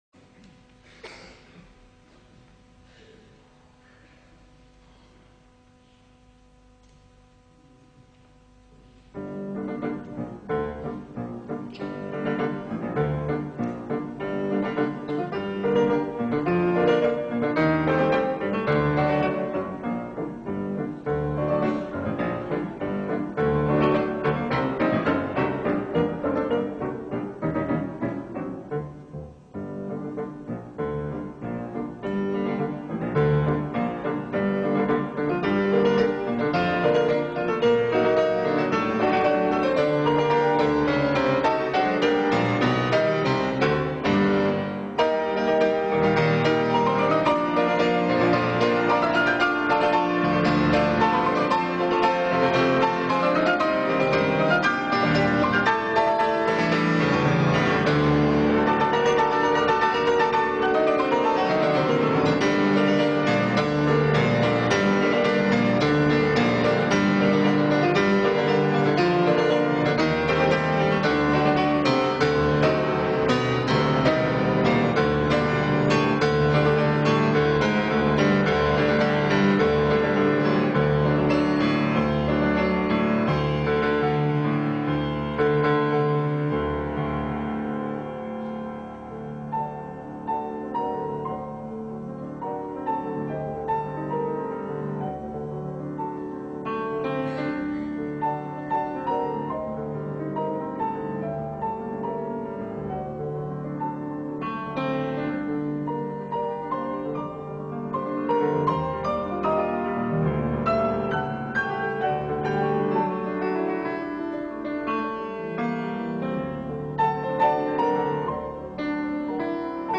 флейта